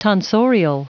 Prononciation du mot tonsorial en anglais (fichier audio)
Prononciation du mot : tonsorial